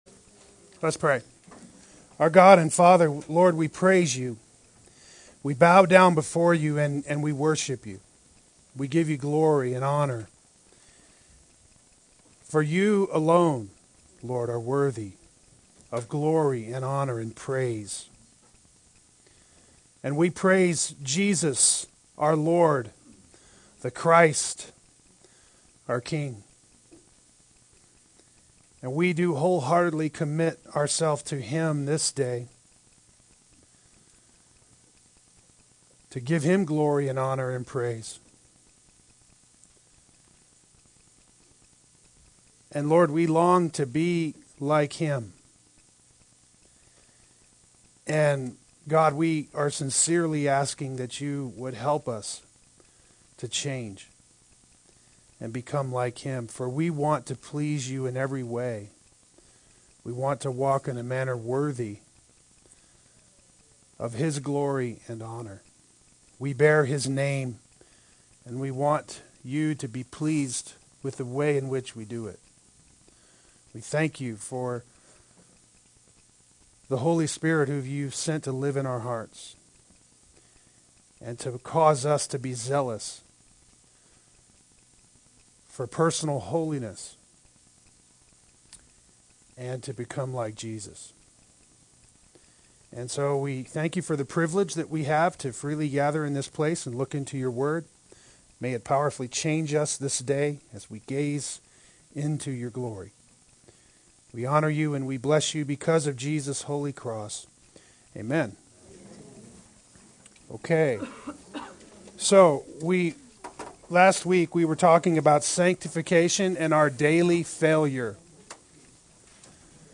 Part 2 Adult Sunday School